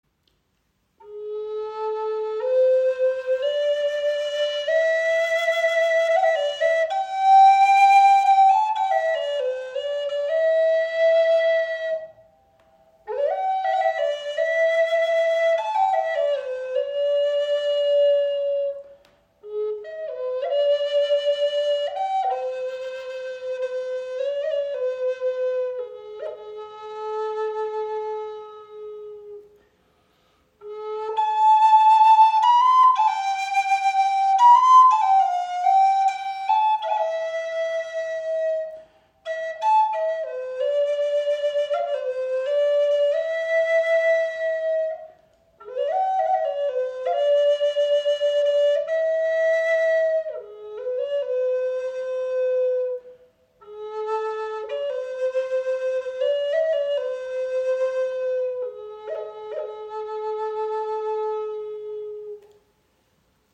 First Hawk Flöte in A-Moll | Pappelholz | 45 cm
Diese handgefertigte Native American Style Flöte in A-Moll wird aus heimischer Pappel gefertigt. Sie bietet einen klaren, ausgewogenen Klang mit warmen, hellen Tönen.